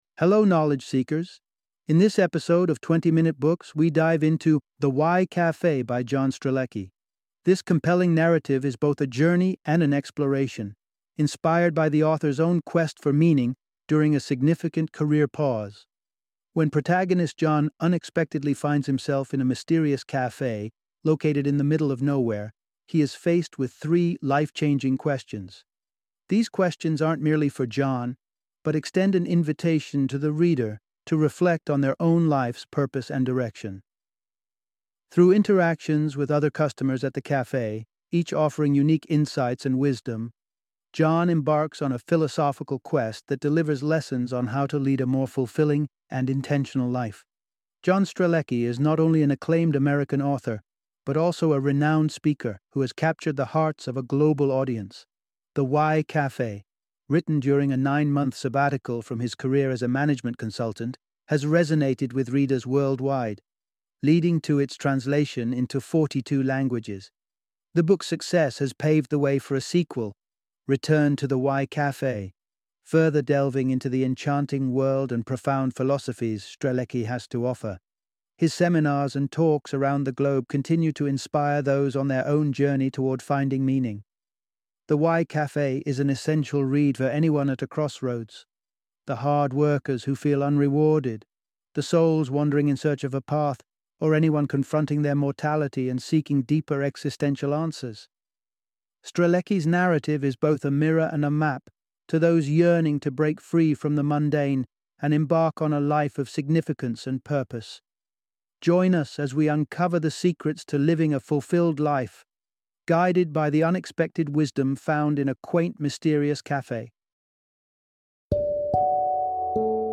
The Why Café - Audiobook Summary